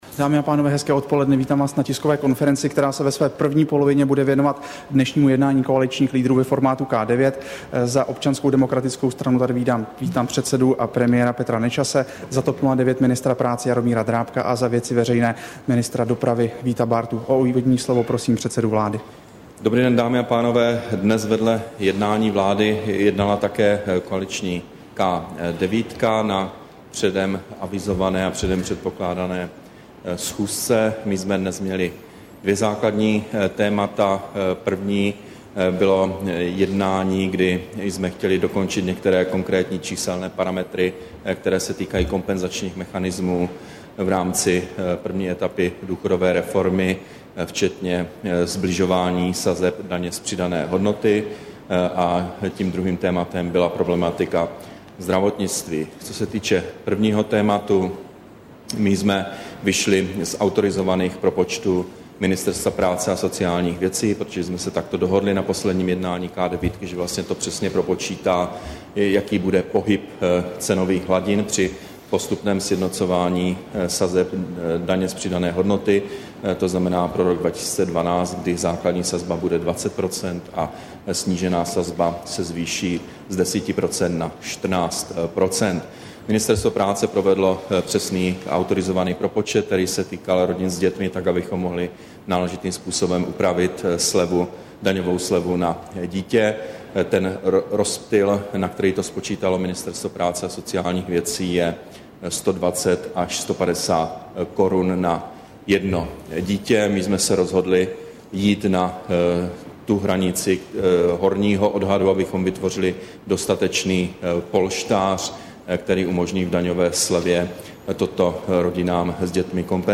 Tisková konference po jednání K9, 17. března 2011